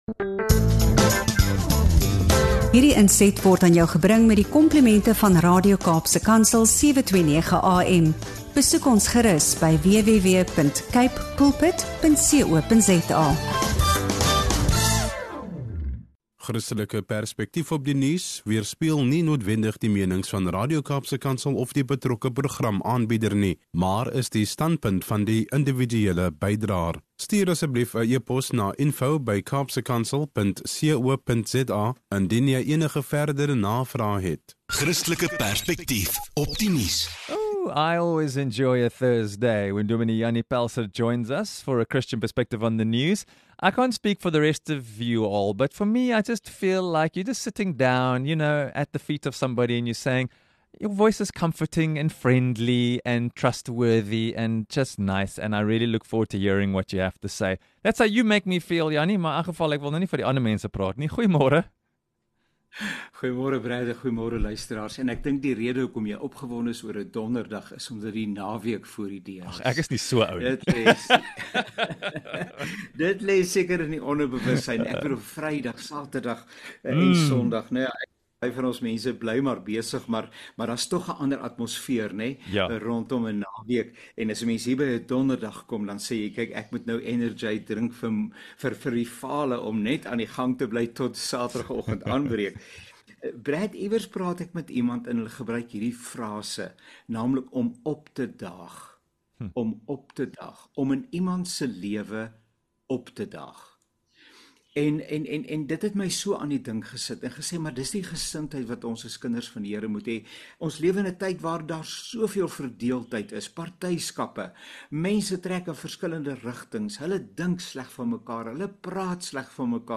Hierdie inspirerende gesprek vanaf Radio Kaapse Kansel se "Christian Perspective"-program moedig luisteraars aan om bewustelik in ander mense se lewens “op te daag”. Die spreker herinner ons daaraan dat ware Christelike diensbaarheid nie oor erkenning of terugbetaling gaan nie, maar oor opregte, onvoorwaardelike liefde – soos Jesus dit vir ons modelleer het.